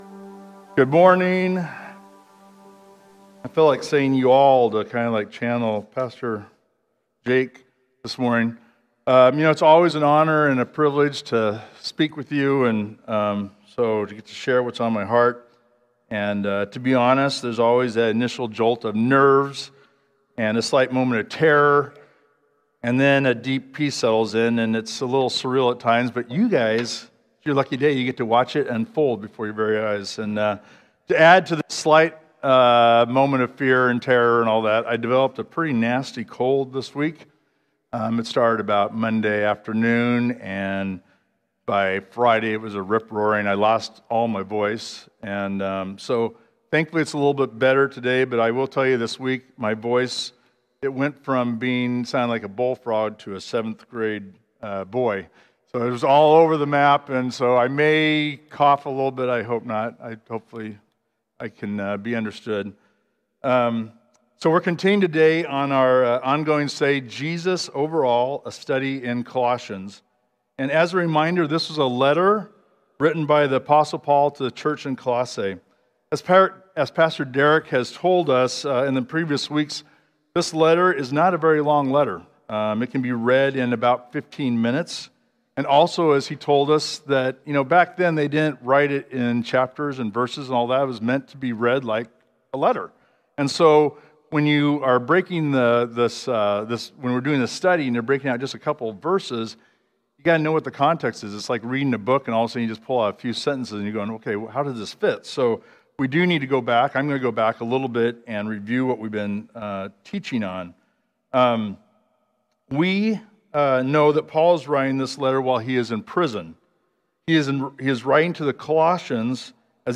sermon-52525.mp3